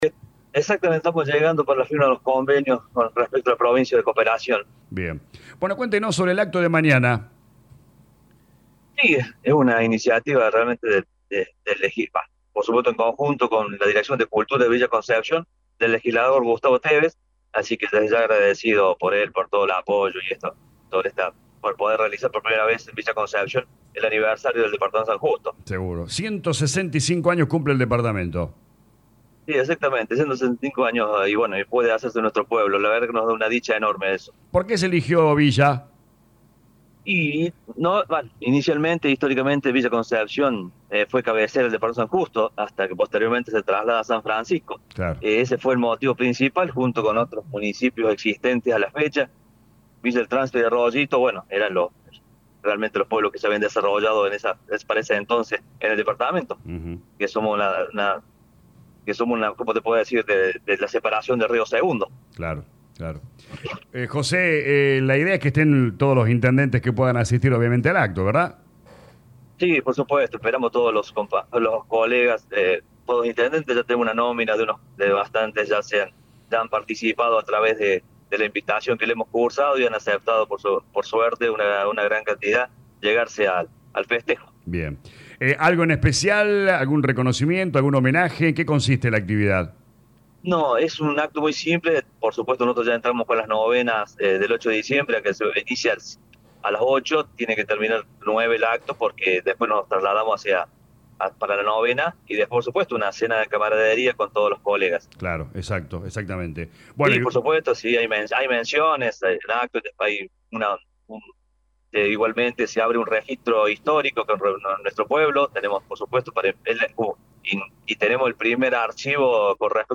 El intendente de Villa Concepción del Tío Efraín Mercado dialogó con LA RADIO 102.9 FM e invitó a todos los vecinos de la región para este viernes 29 de noviembre ya que se realizará el acto protocolar en celebración a los 165 años del Departamento San Justo.
AUDIO – INTENDENTE JOSE EFRAIN MERCADO